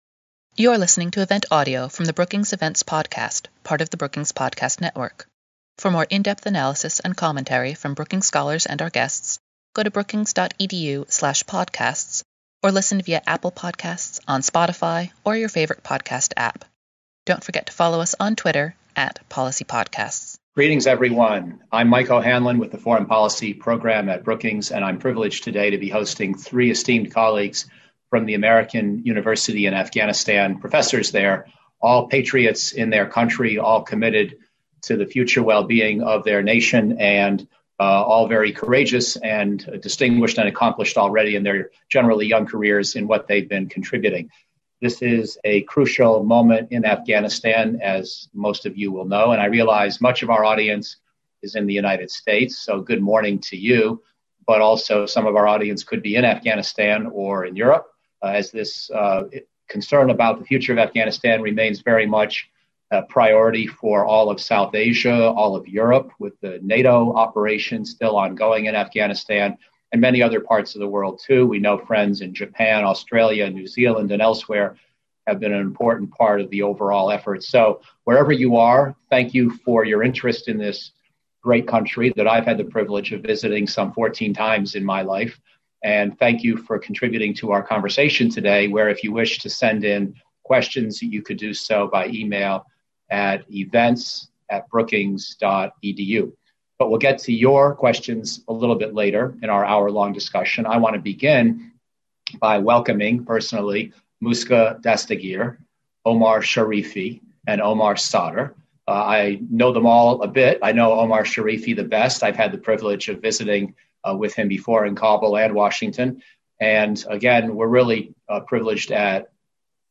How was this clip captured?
Questions from the audience followed the discussion.